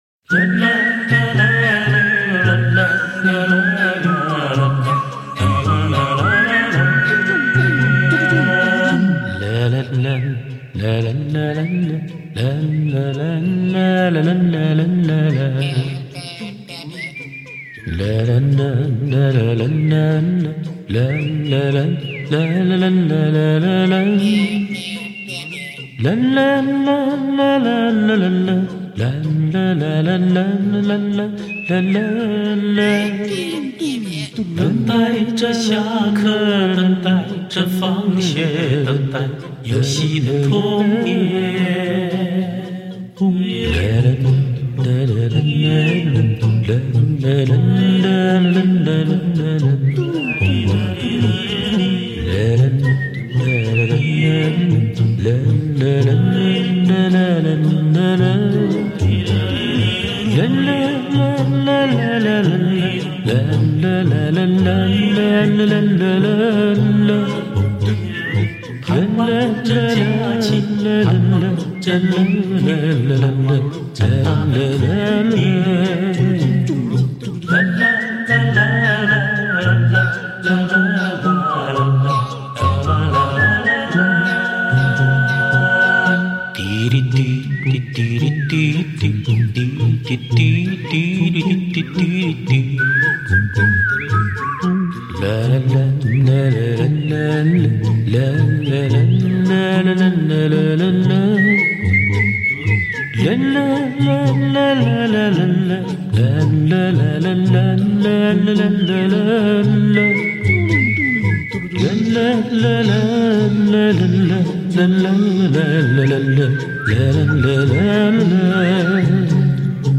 纯人声打造，国内首创，德国老虎鱼后期处理，
细致的编配，让音乐的画面感十足，层次清晰，远近相应，主次相融。
用独有“半声”哼鸣，勾勒出回零归一不浮燥的演唱心态，
其中人声摸拟各种乐器作为伴奏，让人觉得新奇与亲切，尤为值得一听而后快。